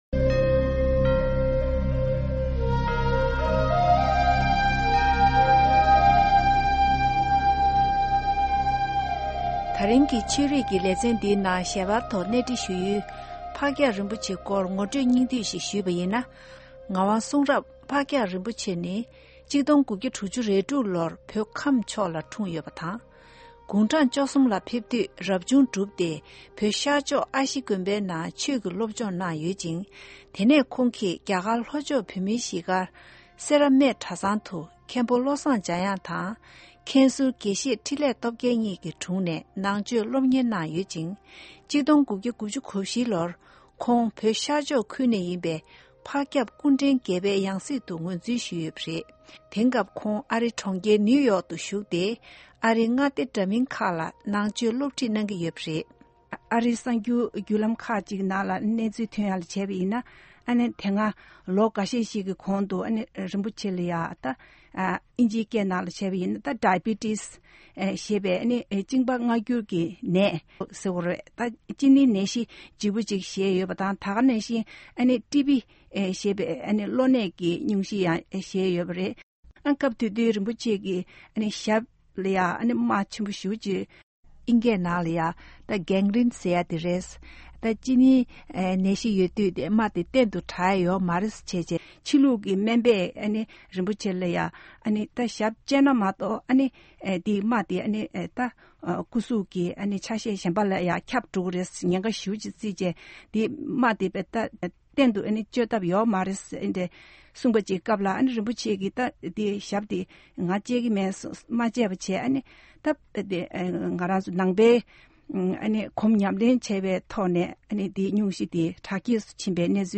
གནས་འདྲི་ཞུས་པ་ཞིག་གསན་གྱི་རེད།